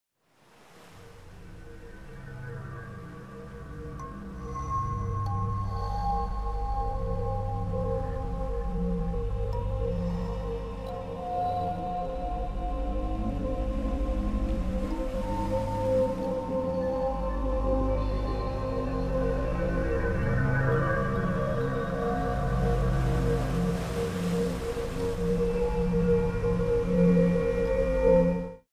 Long tracks of meditative music for stretch classes